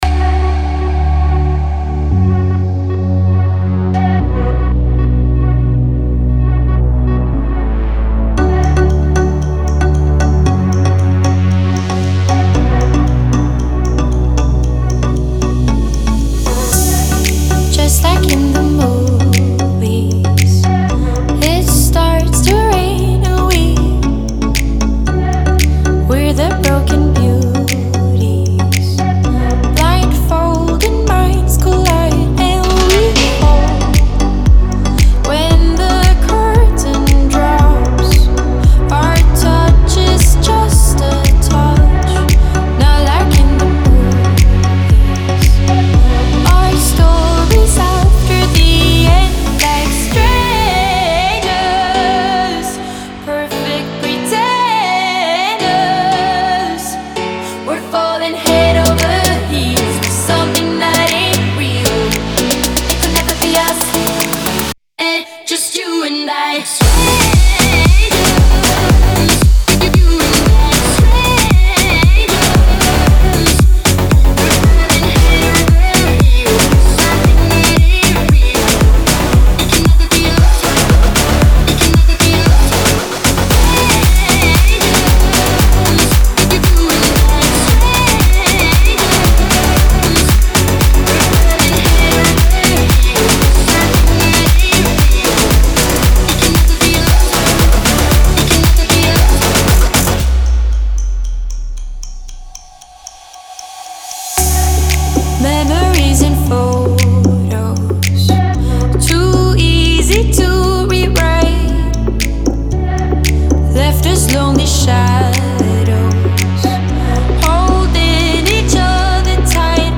Genre : Alternative